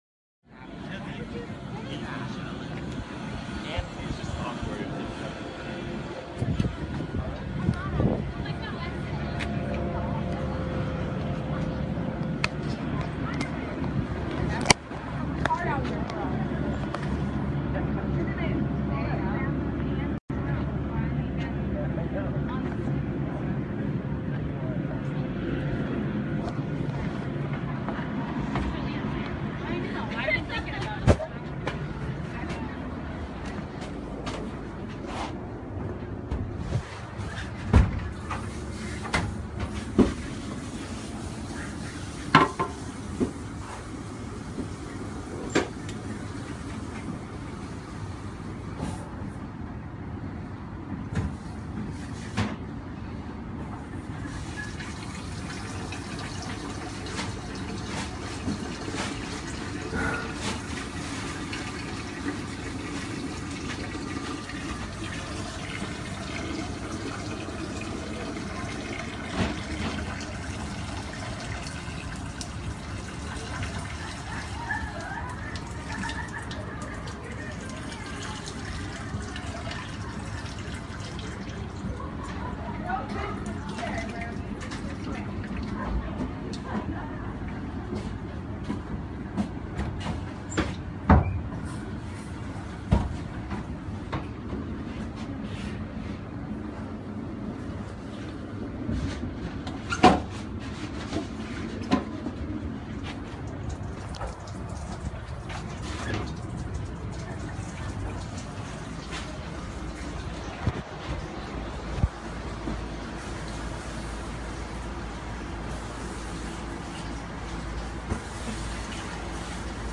男式户外浴室的氛围
描述：男士户外浴室氛围
标签： 男装 户外 浴室 环境
声道立体声